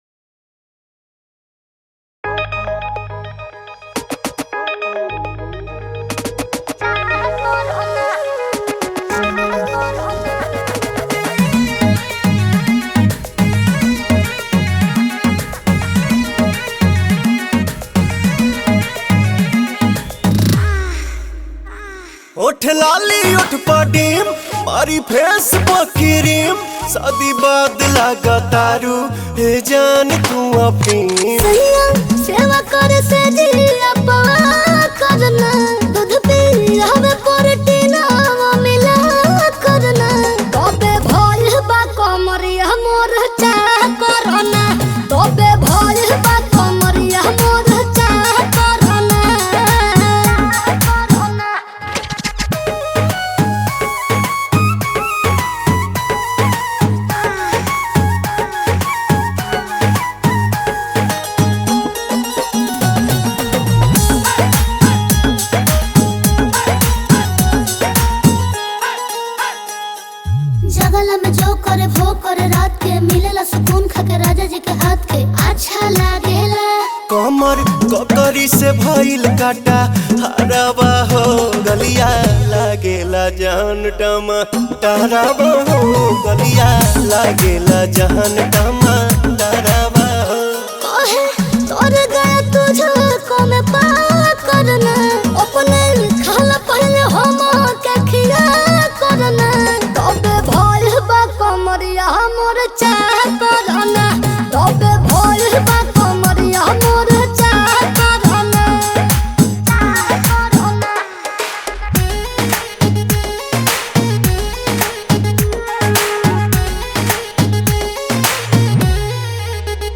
New Bhojpuri Song 2025